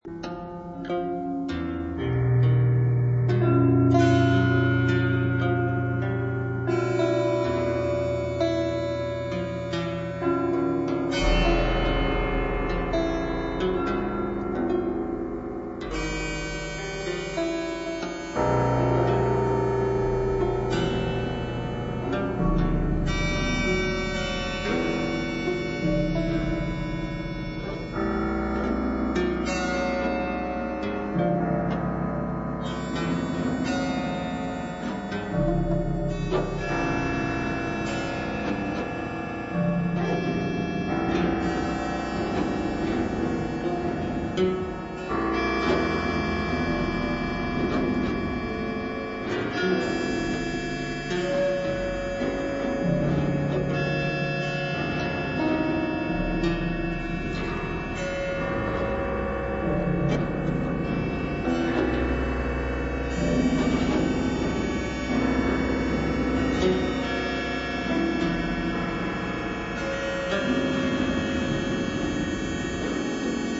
for 3 zithers and electronics
for organ and electronics